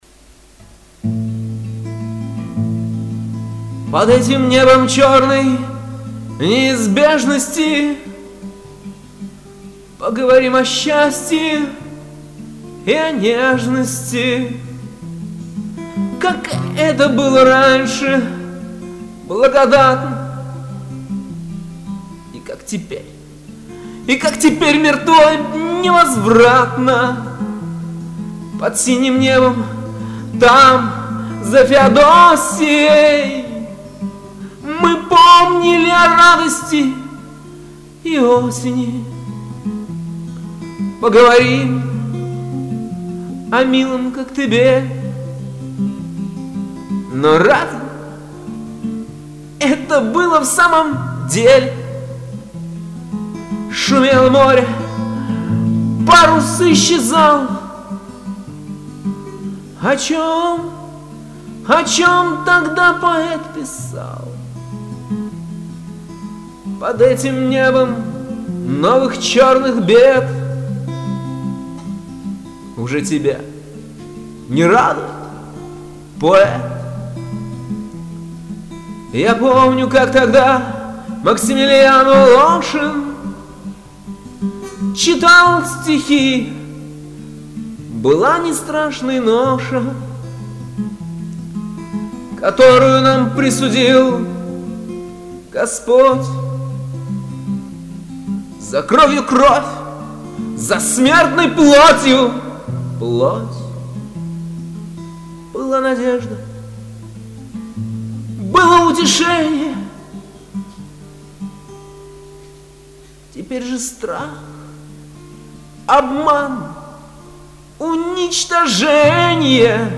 • Автор текста: Трубецкой Юрий
• Жанр: Авторская песня